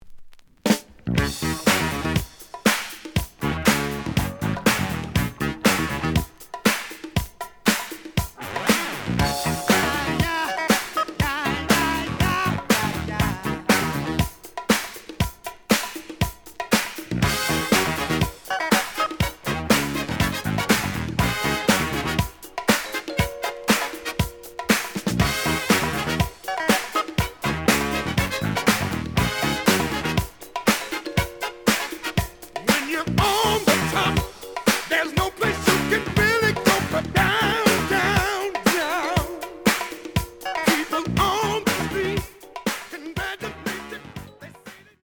The audio sample is recorded from the actual item.
●Genre: Funk, 70's Funk
Slight edge warp.